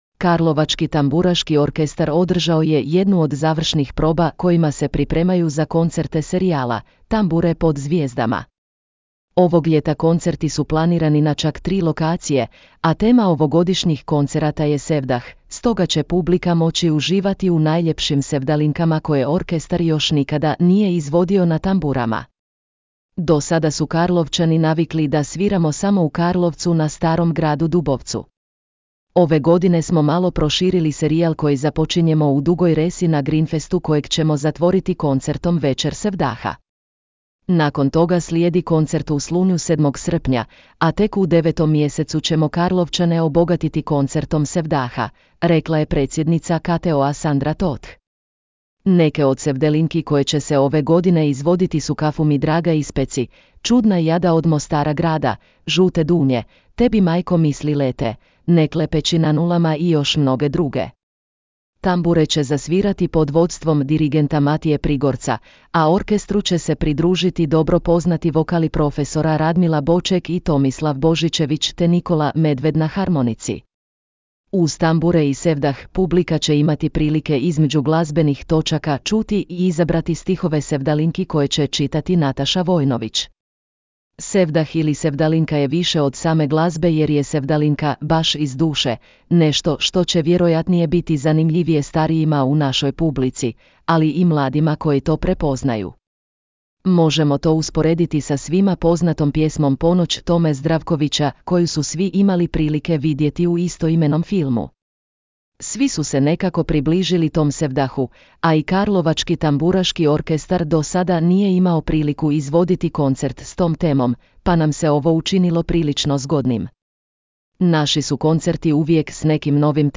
„Tambure pod zvijezdama“; Karlovački tamburaški orkestar održao probu za koncerte serijala s temom sevdaha
Na probama, koje traju i po tri sata, mladi glazbenici intenzivno se pripremaju za koncerte.